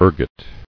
[er·got]